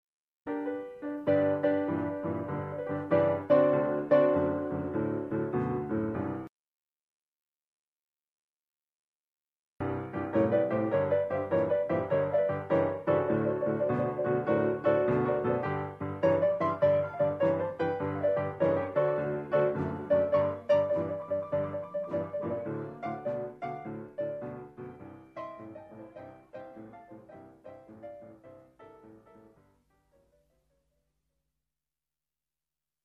просто создаёт ретро-настроение